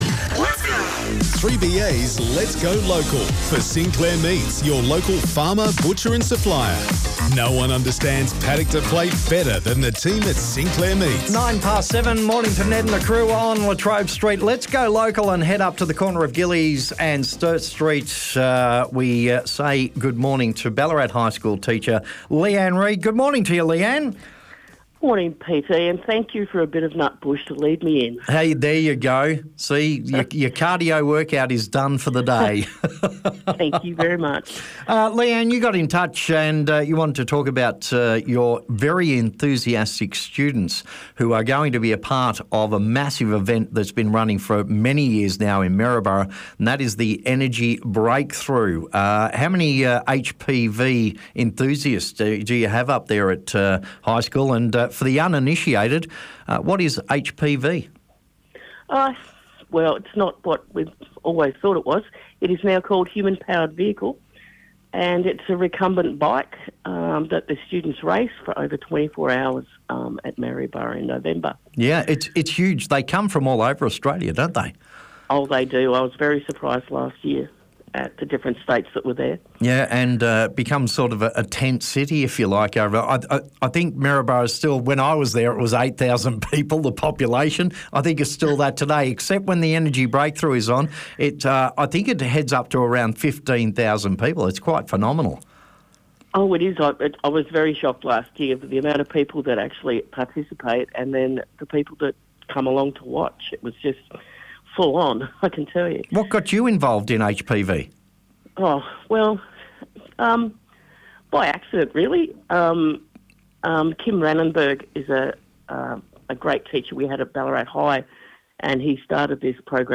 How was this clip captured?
Listen to the recent interview on Ballarat's 3BA about our Energy Breakthrough program and our fundraising initiatives.